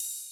ride_cym1.ogg